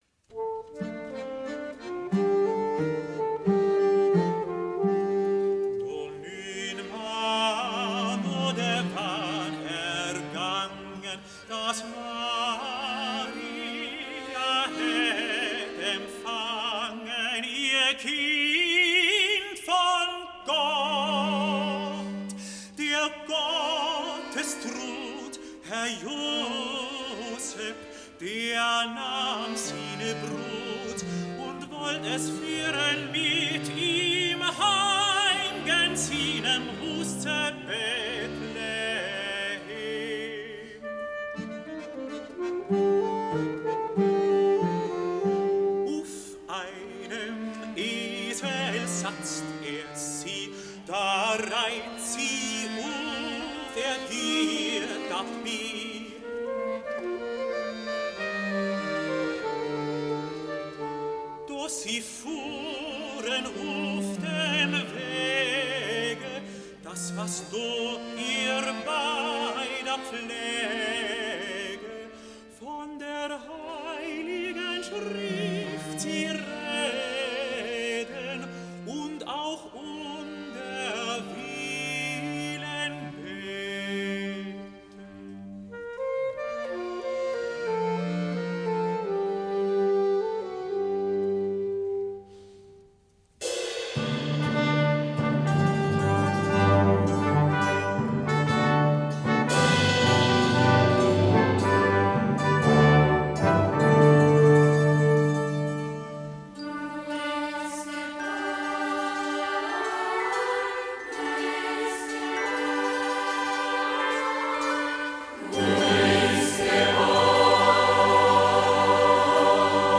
Das Admonter Konzertereignis